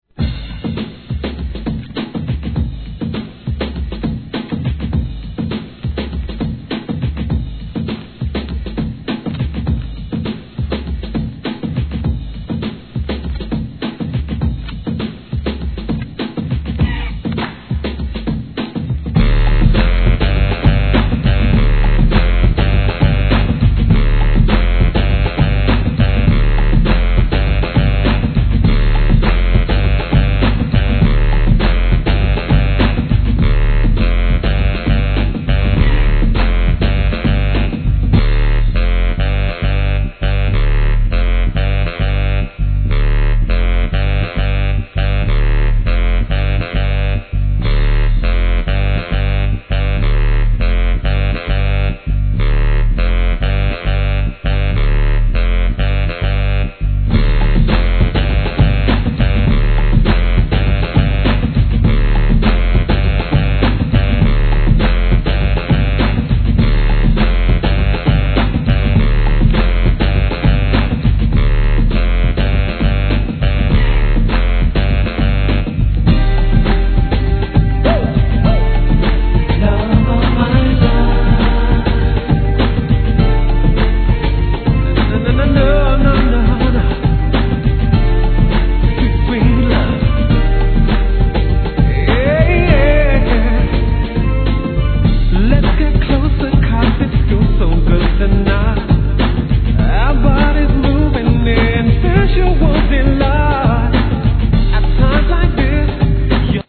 HIP HOP/R&B
1990年、渋いフォーンLOOPのUKらしいグランド・ビート!!